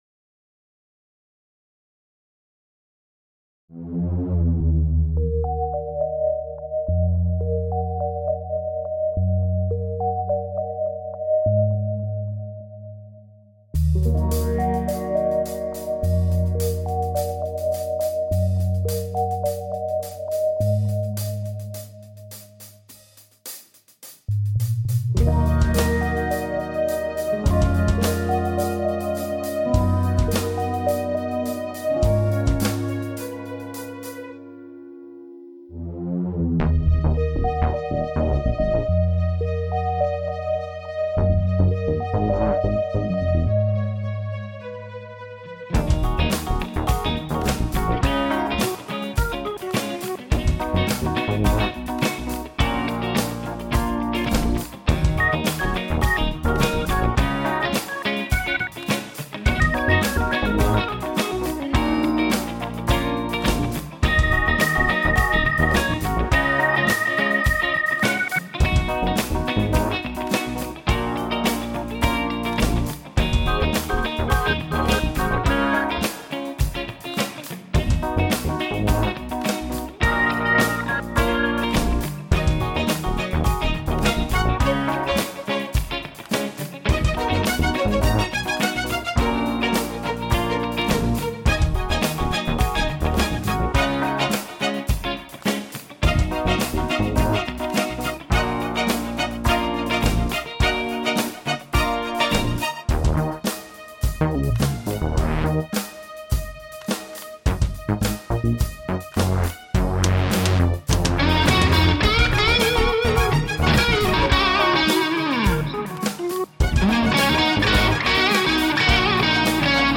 Ich hatte die große Freude, dass ein Keyboarder mich gefragt hat, ob mir etwas zu seiner Musik einfällt...
Ich hoffe, meine Gitarrenarbeit unterstützt das Stück.